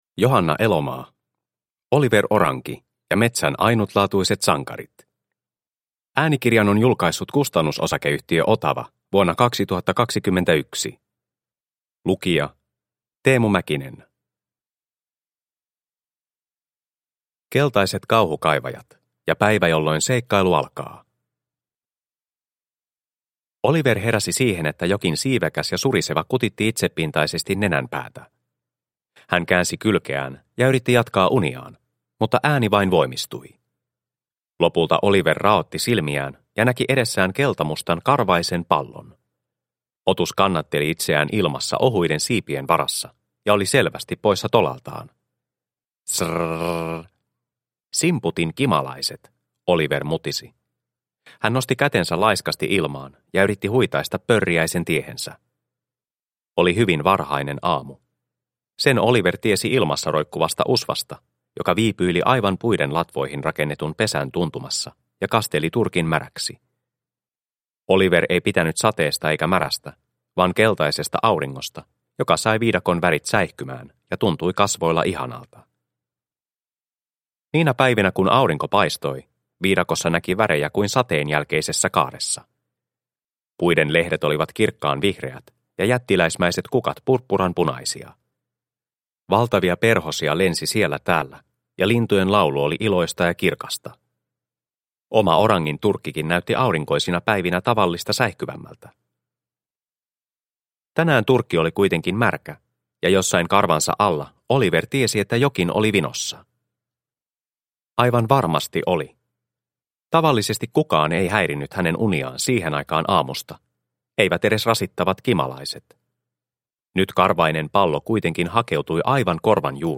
Oliver Oranki ja metsän ainutlaatuiset sankarit – Ljudbok – Laddas ner